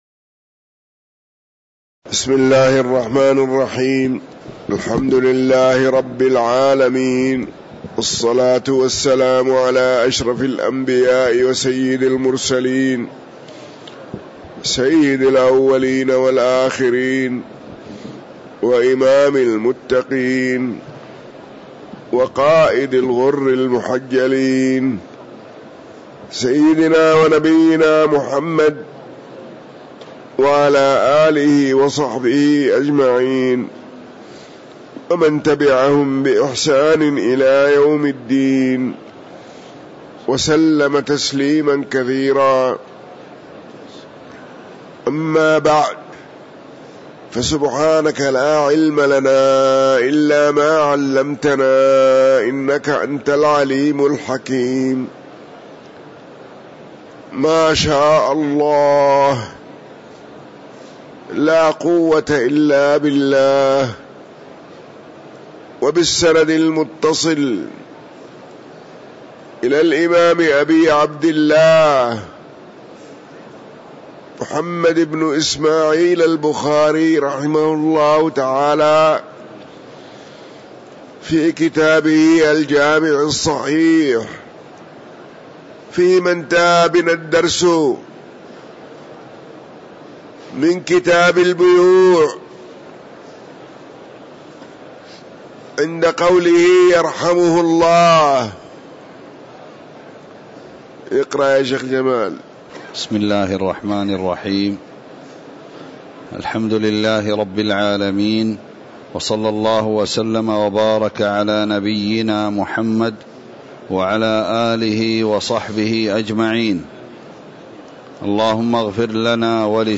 تاريخ النشر ١٢ ربيع الأول ١٤٤٥ هـ المكان: المسجد النبوي الشيخ